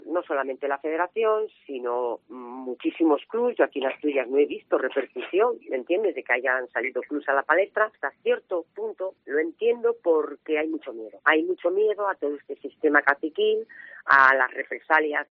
DECLARACIONES A COPE